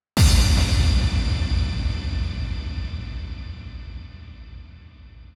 SouthSide Stomp (3) .wav